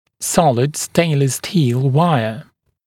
[‘sɔlɪd ‘steɪnləs stiːl ‘waɪə][‘солид ‘стэйнлэс сти:л ‘уайэ]цельная дуга из нержавеющей стали